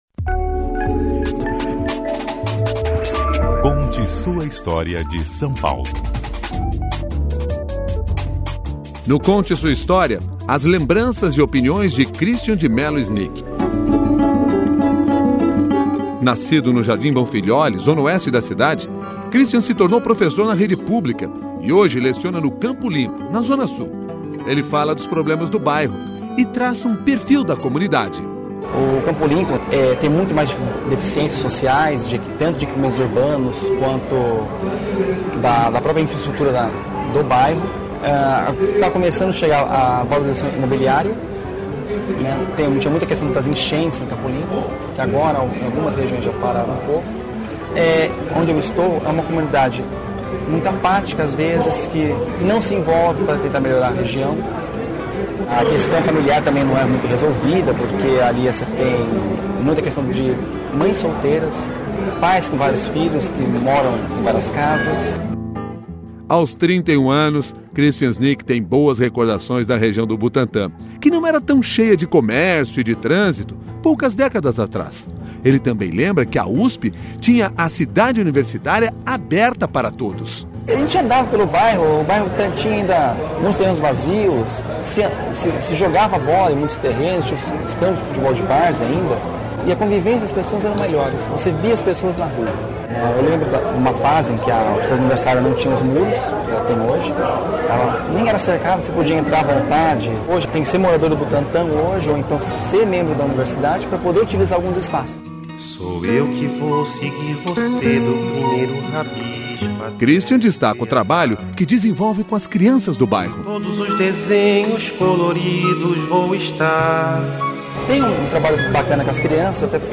gravado pelo Museu da Pessoa
O Conte Sua História de São Paulo vai ao ar, sábados, às 10 e meia da manhã, no CBN SP.